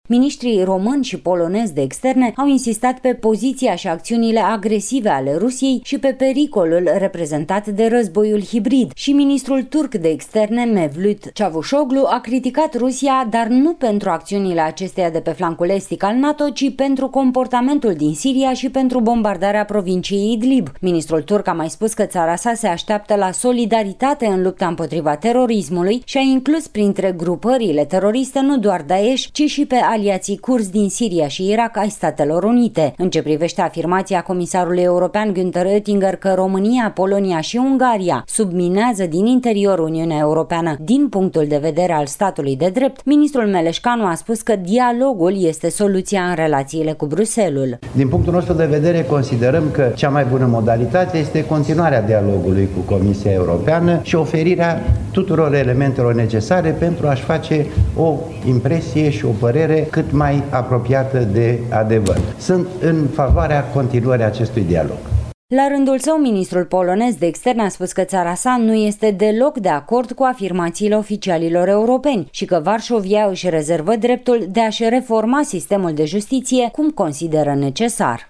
Este dreptul oricărui oficial european să îsi exprime punctul de vedere dar este important ca aceste puncte de vedere sa aibă o bază solidă, a declarat ministrul de externe, Teodor Meleșcanu, la finalul reuniunii Trilateralei România-Polonia-Turcia. Recent, mai mulți oficiali europeni au criticat situația statului de drept și a justiției din România. Temele principale ale reuniunii la nivel de miniștri de externe de la București au fost evoluţiile de securitate la nivel regional şi internaţional şi priorităţile NATO.